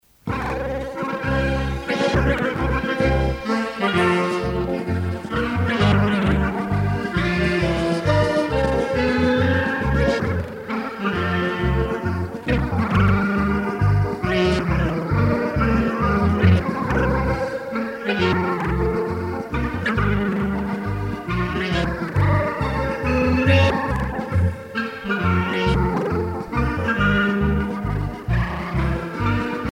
danse : marche
Orchestre de variétés
Pièce musicale éditée